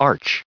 added pronounciation and merriam webster audio
77_arch.ogg